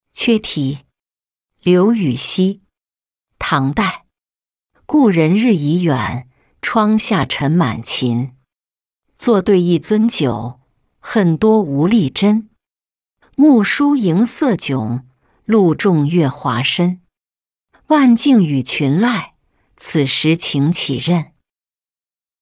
缺题-音频朗读